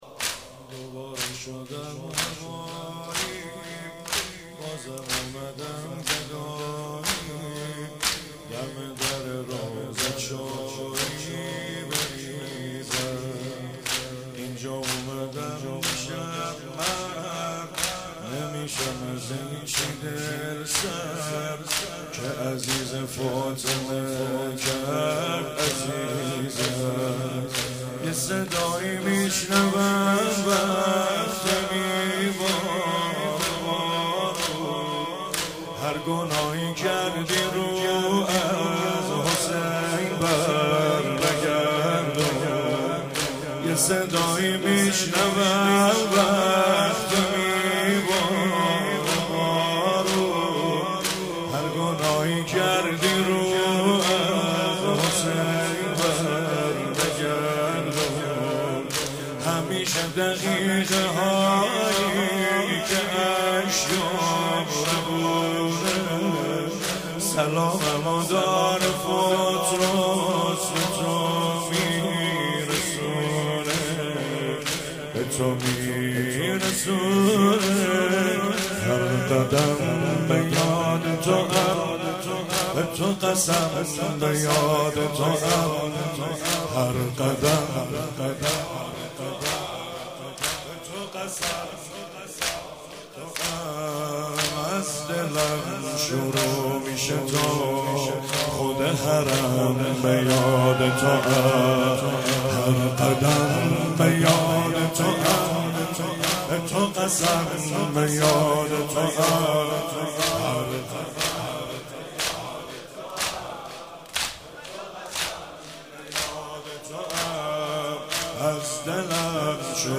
25 صفر97 - شب دوم - زمینه - دوباره شدم هوایی